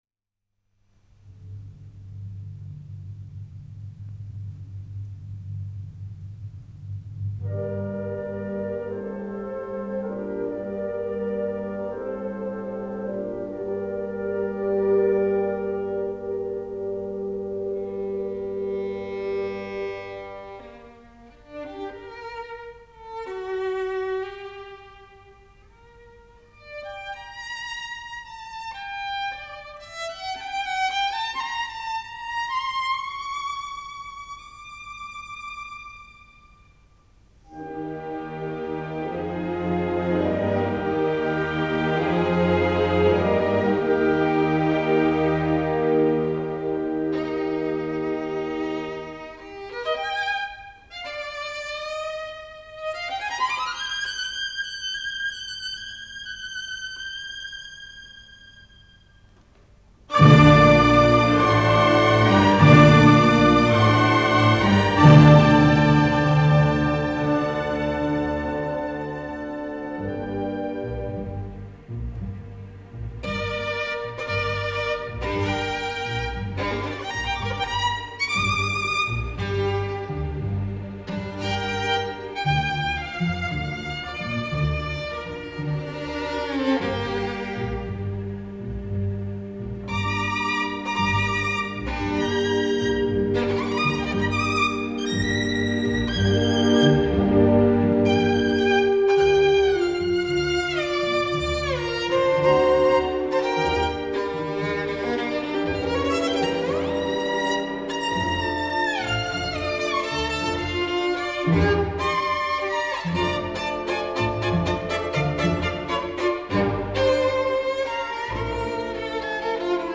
Continuing our string of historic concerts this week with a broadcast performance by the Radio Symphony of Stuttgart, conducted by Hans Mueller-Kray and featuring the legendary violinist Gerhard Taschner in Violin Concerto Number 1 by Max Bruch, recorded by South German Radio in 1954.